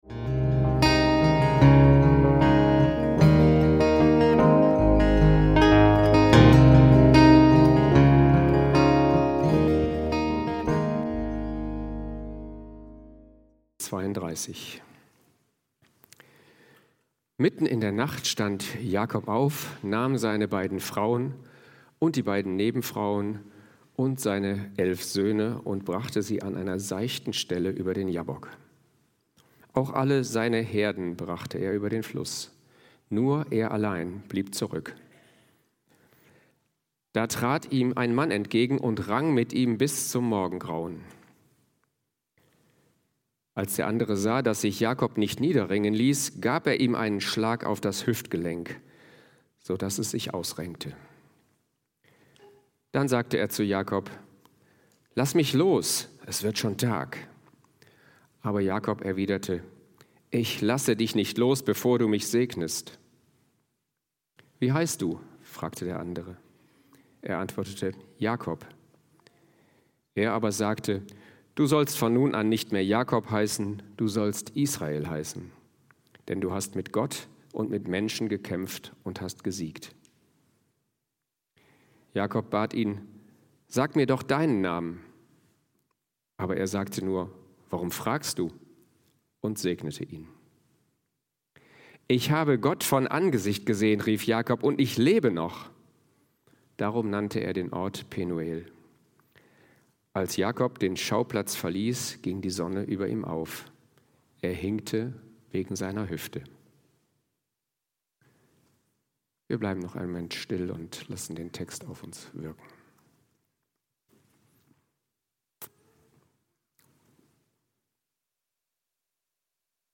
Jakob kämpft mit Gott - Predigt vom 04.05.2025 ~ FeG Bochum Predigt Podcast